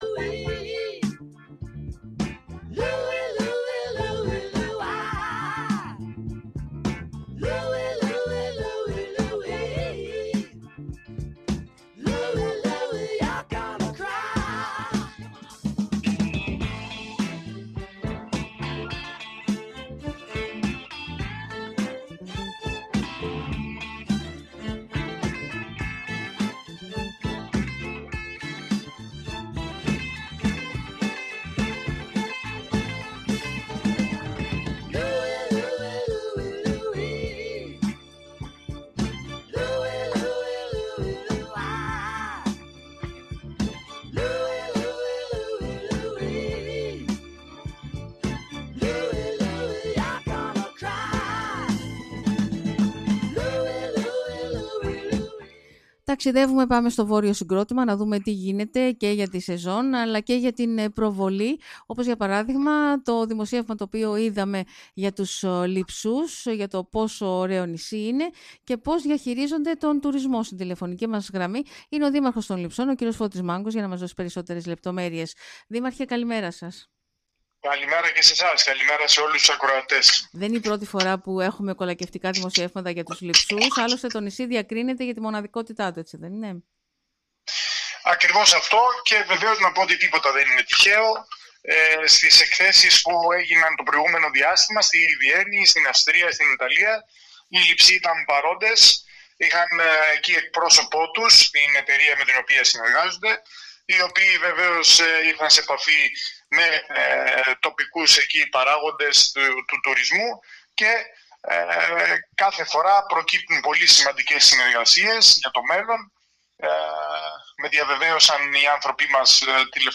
Στις προοπτικές της φετινής τουριστικής σεζόν αλλά και στις ετοιμασίες για το Πάσχα αναφέρθηκε  μιλώντας σήμερα στον Sky ο δήμαρχος των Λειψών κ. Φώτης Μάγγος με αφορμή άλλο ένα δημοσίευμα ξένου μέσου ενημέρωσης που εκθειάζει το  νησί και τις  ομορφιές του.